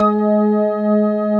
B3 ROCKG#3.wav